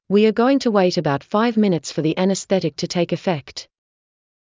ｳｨｰ ｱｰ ｺﾞｰｲﾝｸﾞ ﾄｩ ｳｪｲﾄ ｱﾊﾞｳﾄ ﾌｧｲﾌﾞ ﾐﾆｯﾂ ﾌｫｰ ｼﾞ ｱﾈｽｾﾃｨｯｸ ﾄｩ ﾃｲｸ ｴﾌｪｸﾄ